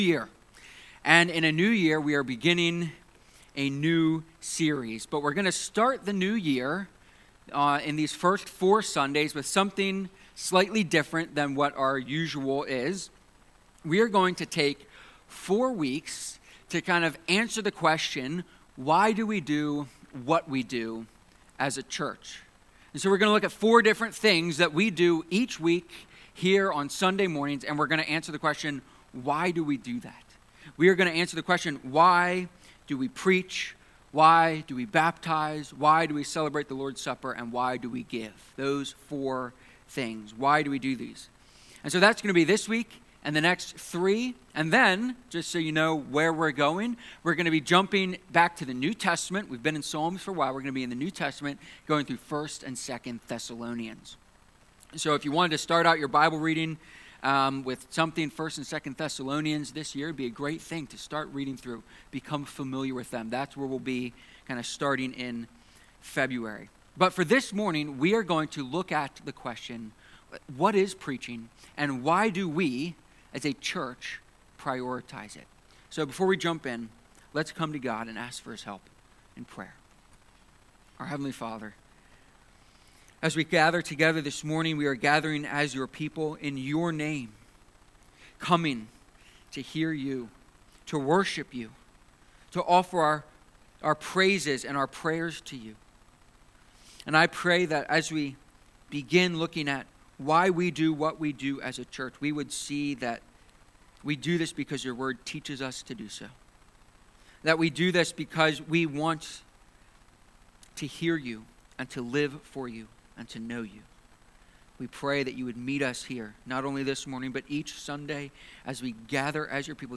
Subscribe Tagged with expository preaching , sermons
Preaching-sermon.mp3